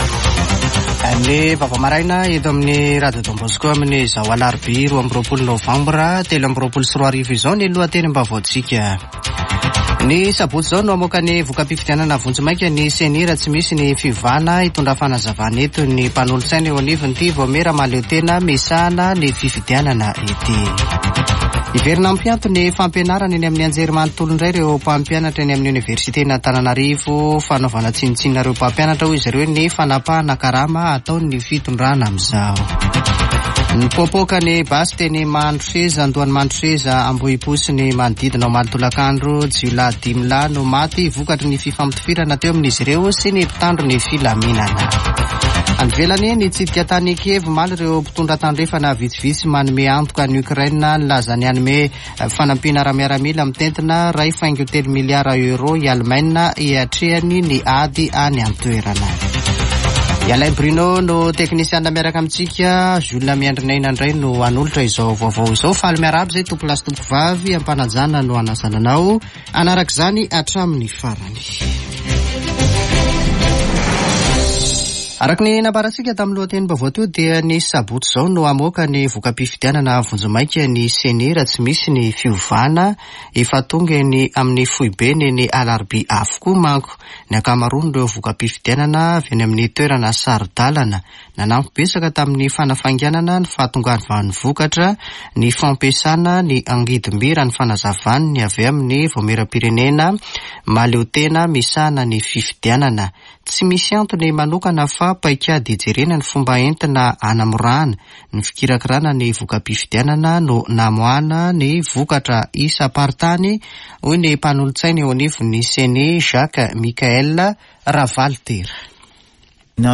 [Vaovao maraina] Alarobia 22 nôvambra 2023